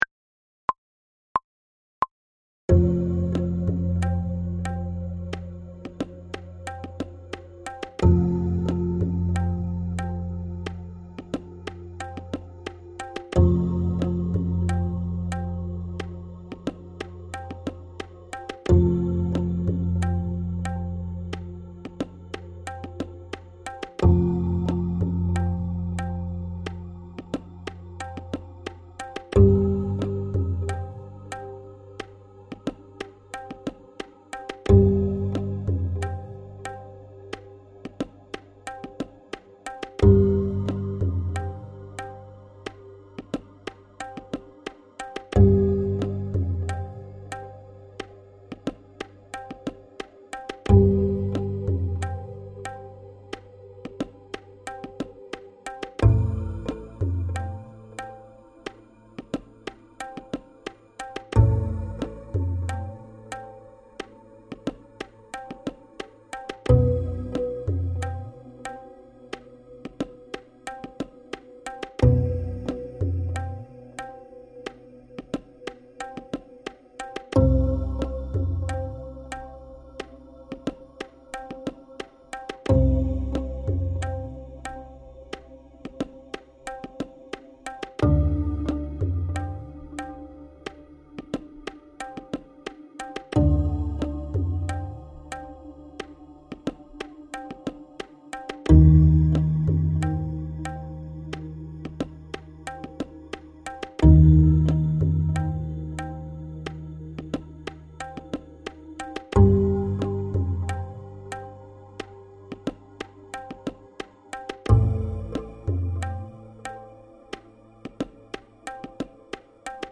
Näide nr 4 (90 BPM) fono: